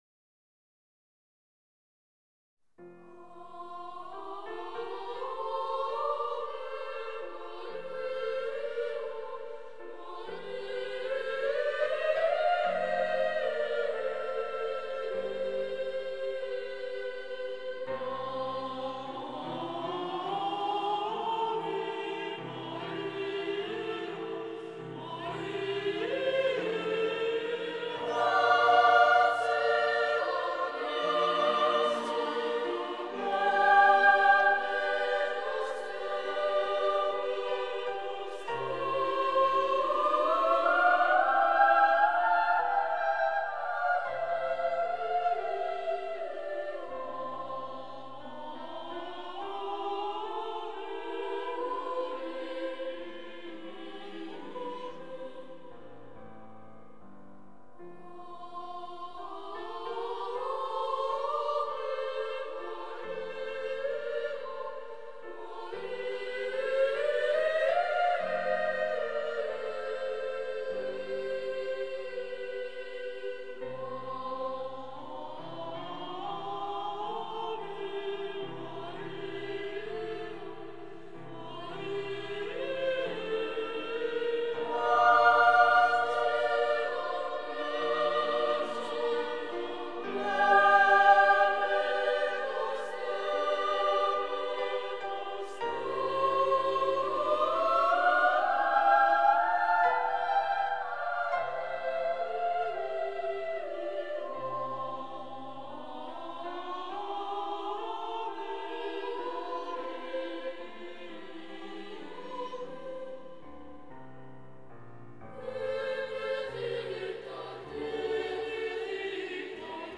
Orgue
Католический хор мальчиков